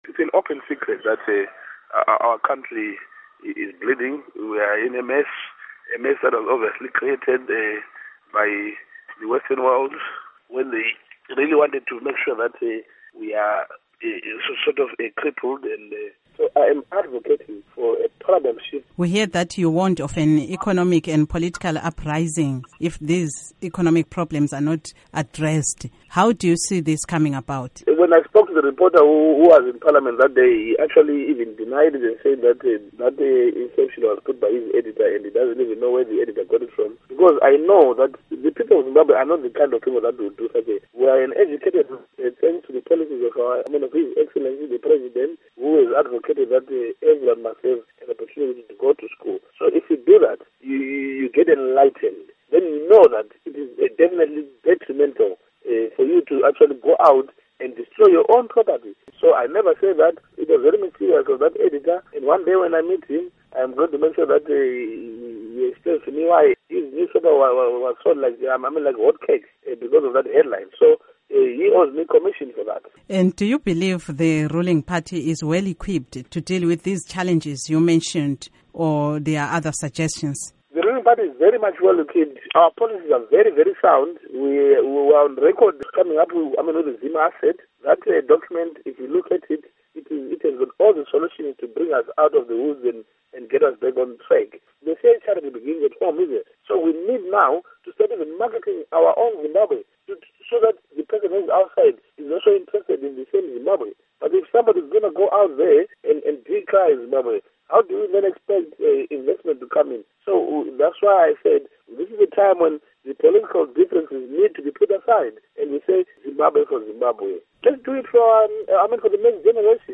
Interview with Joseph Tshuma